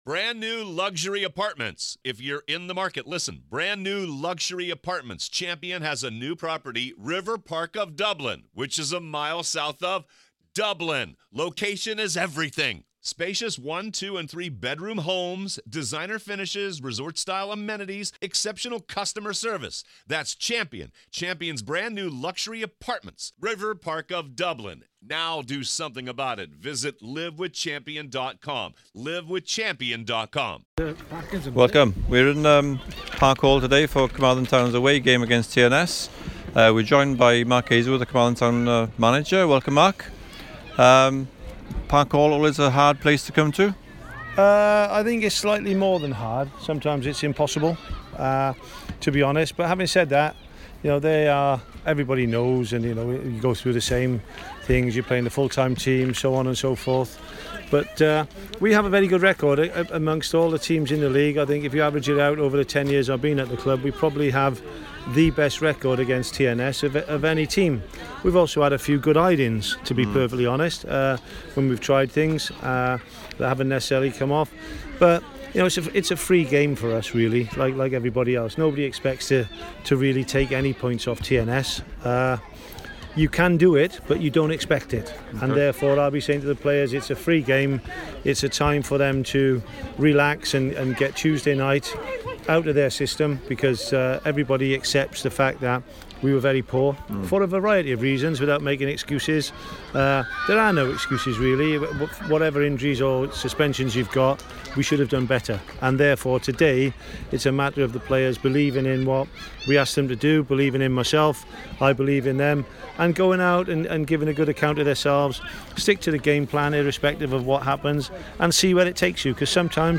post match interview